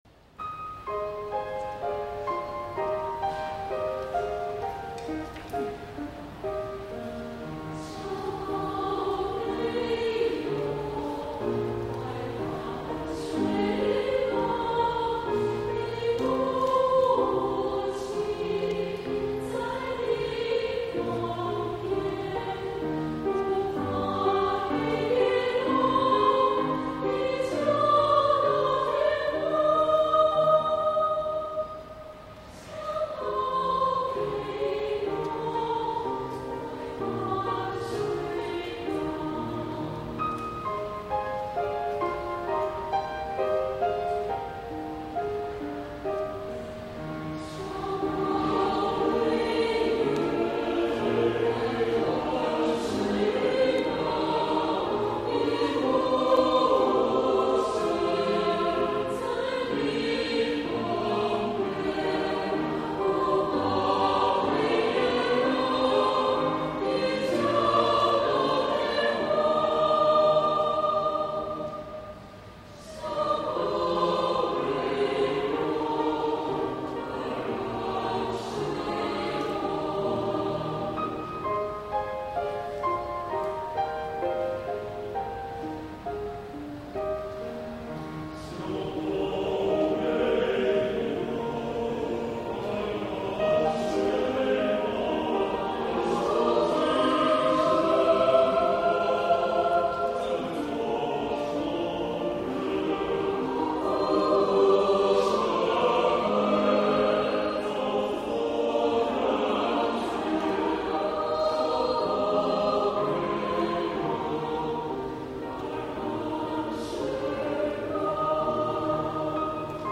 除定時練習外，每年也騁請聲樂 家來團作培訓，是本澳一支充滿活力的 合唱團。